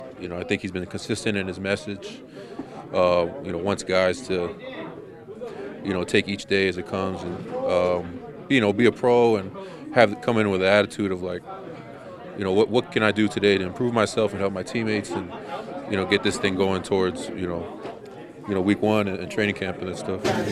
Veteran guard Isaac Seumalo said the players are receptive to Smith.